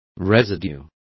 Complete with pronunciation of the translation of residue.